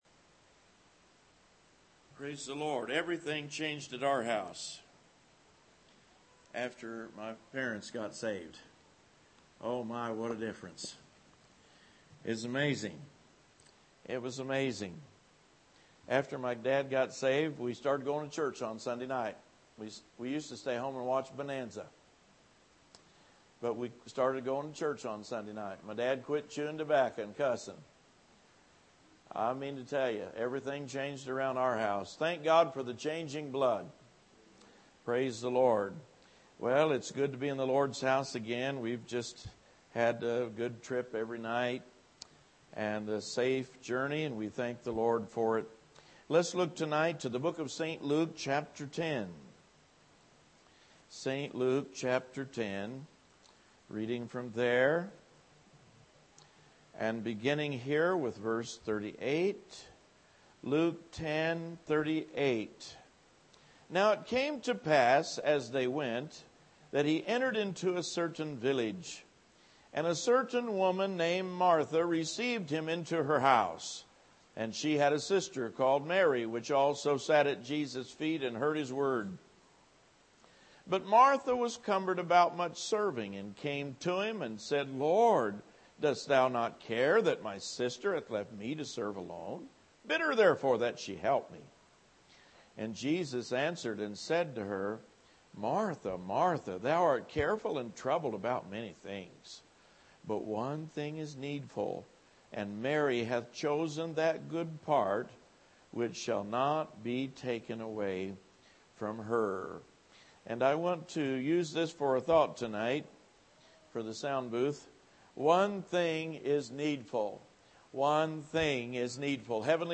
Series: Spring Revival 2015 Tagged with distraction , martha , mary , worship